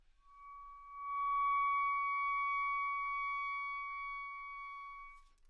单簧管单音 " 单簧管 C6
描述：在巴塞罗那Universitat Pompeu Fabra音乐技术集团的goodsounds.org项目的背景下录制。单音乐器声音的Goodsound数据集。
标签： 纽曼-U87 单簧管 单注 多重采样 好声音 Csharp6
声道立体声